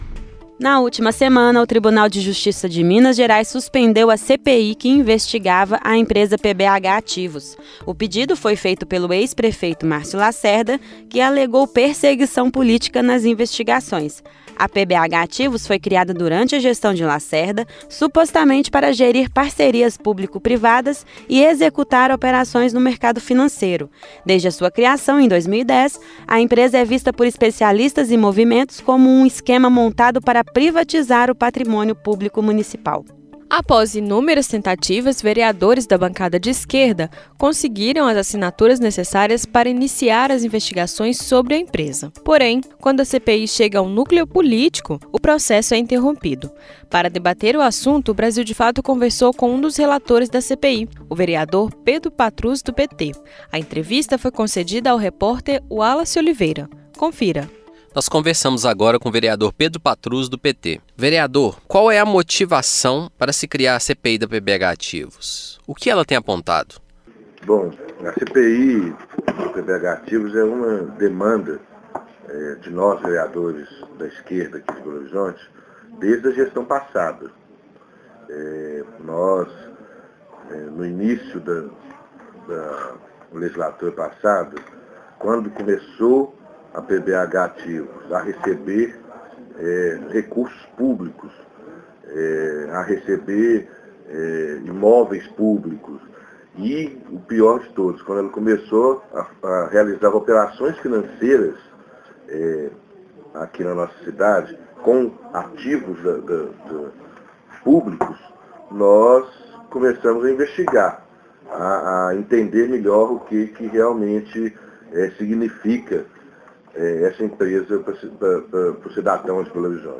Chegada ao núcleo político motivou a suspensão da CPI | Entrevista